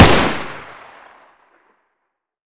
sr3m_fire.wav